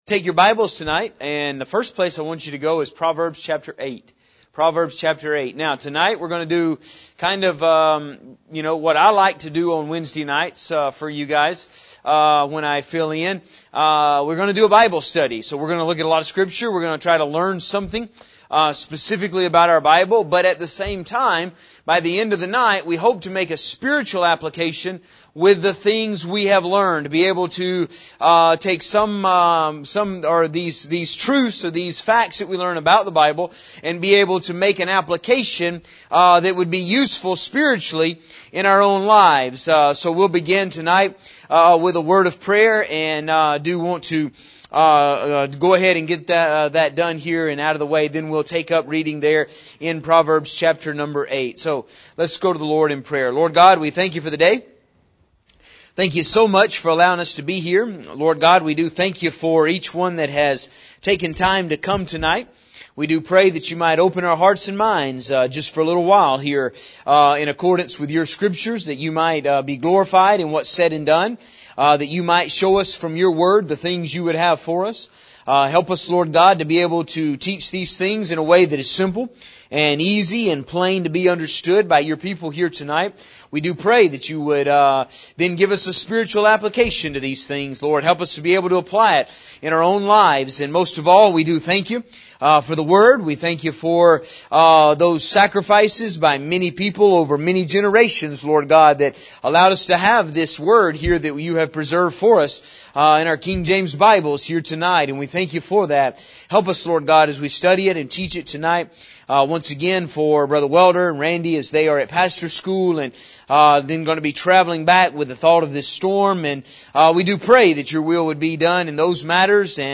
The word does have several other meanings but we use it this way for the purpose of this sermon. In this sermon we will study some scripture concerning creation and the deep and then make a spiritual application for us in regards to looking up and keeping our compass pointed in the right direction.